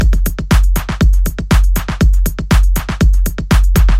标签： 120 bpm Drum And Bass Loops Drum Loops 689.24 KB wav Key : Unknown
声道立体声